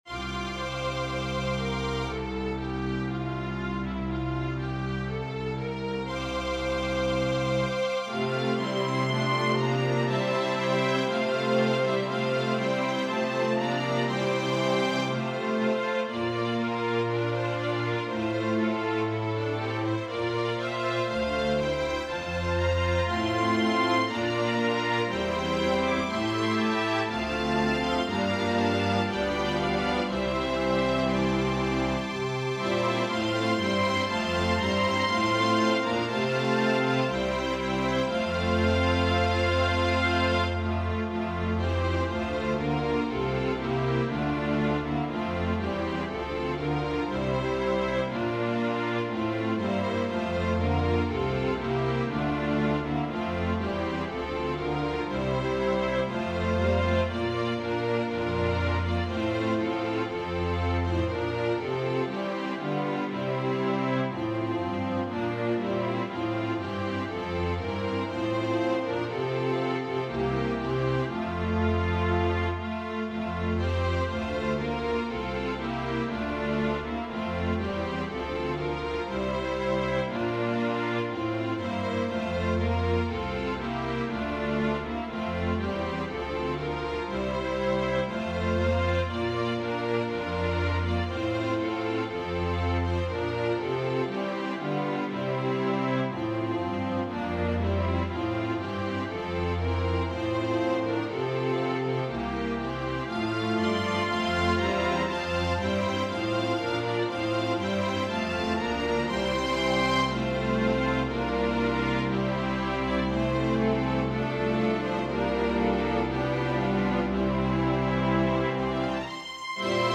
Organ/Organ Accompaniment
Voicing/Instrumentation: Organ/Organ Accompaniment We also have other 20 arrangements of " While Shepherds Watched Their Flocks By Night ".